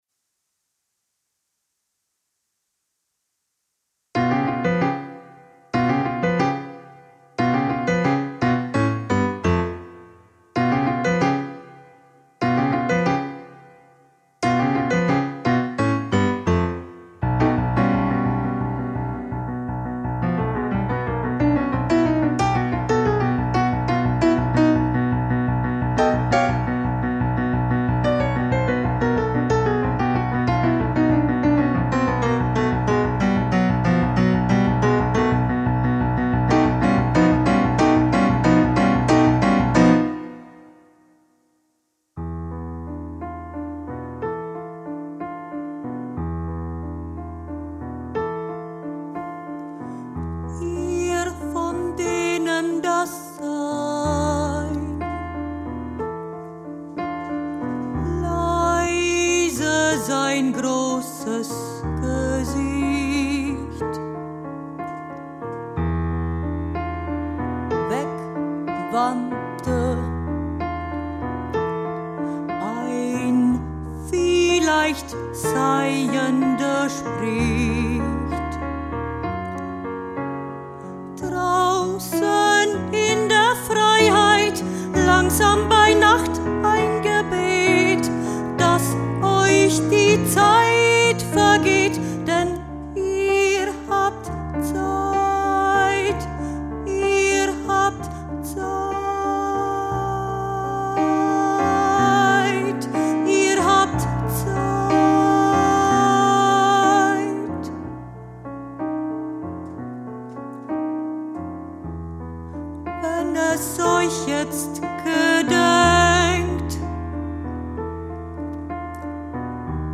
piano, zang